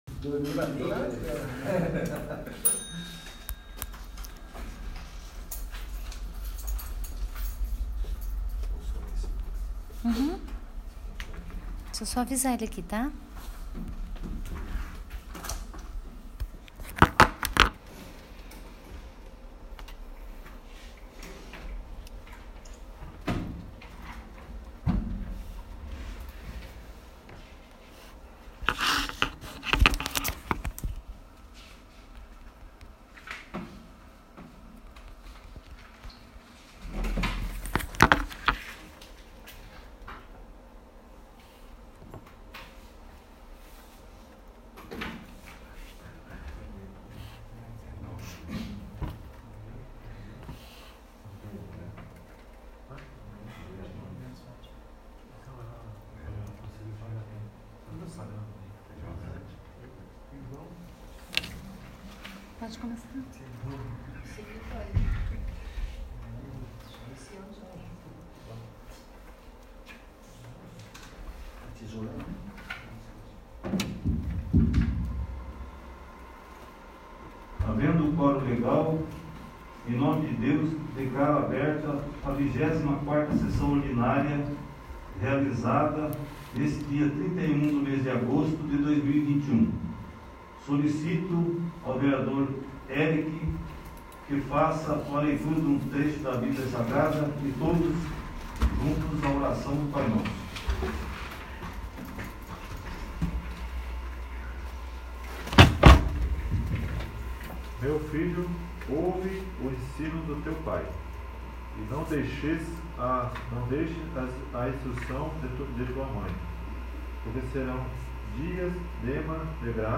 24º. Sessão Ordinária